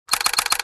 Professional-camera-shutter-multiple-shots.mp3